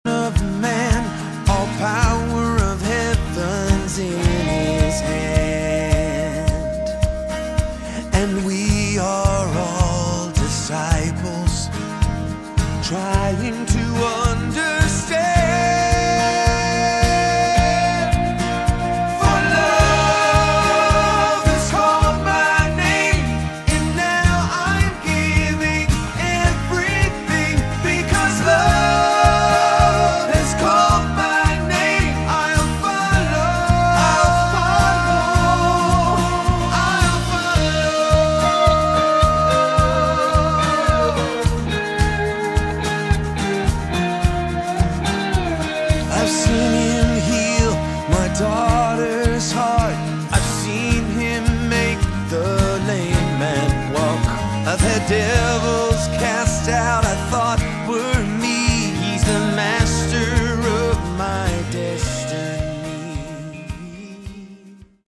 Category: Prog / AOR
keyboards, guitar
bass
drums